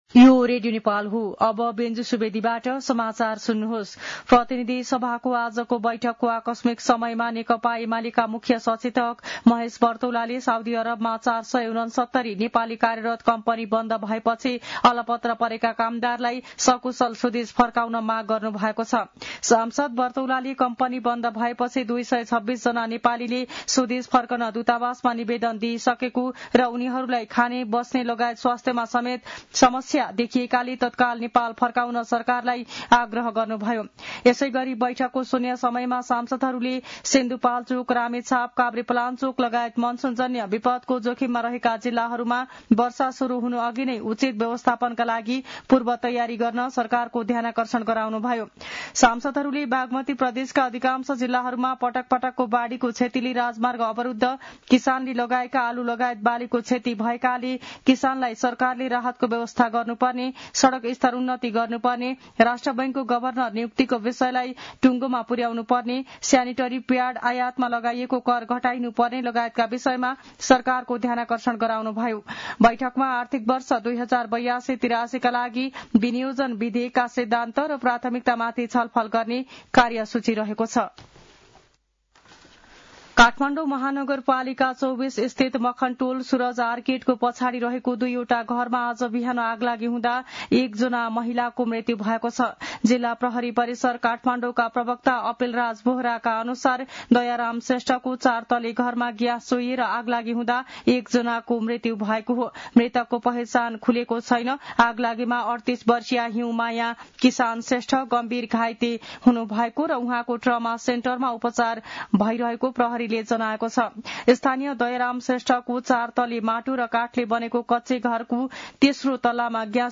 मध्यान्ह १२ बजेको नेपाली समाचार : ३१ वैशाख , २०८२